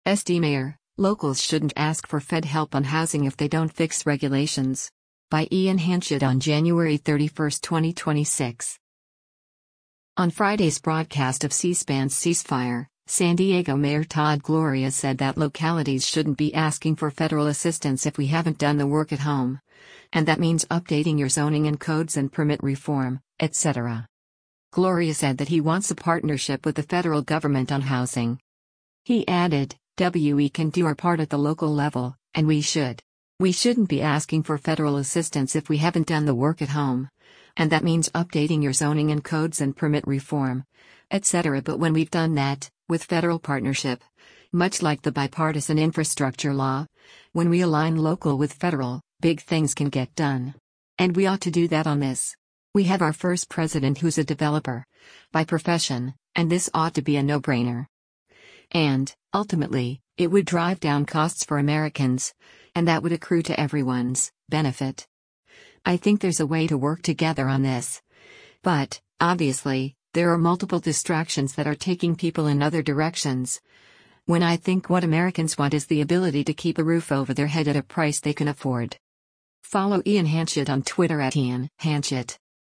On Friday’s broadcast of C-SPAN’s “Ceasefire,” San Diego Mayor Todd Gloria said that localities “shouldn’t be asking for federal assistance if we haven’t done the work at home, and that means updating your zoning and codes and permit reform, etc.”